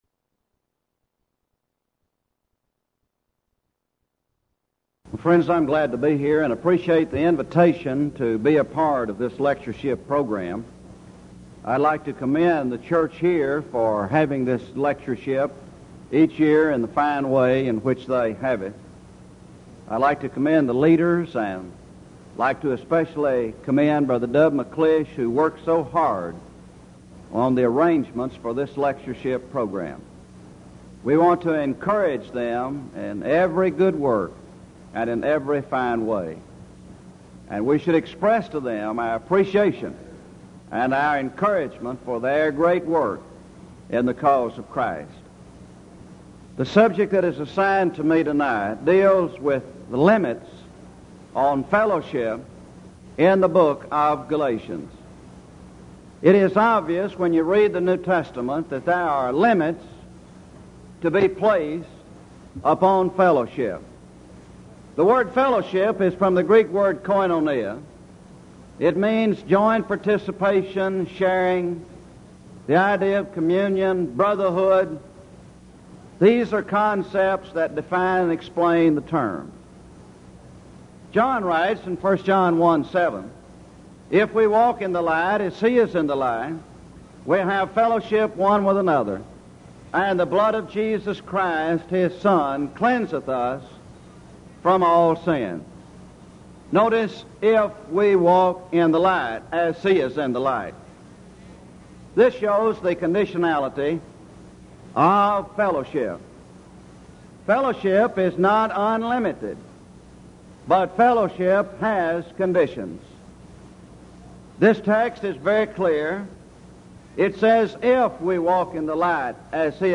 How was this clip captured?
Event: 1986 Denton Lectures Theme/Title: Studies in Galatians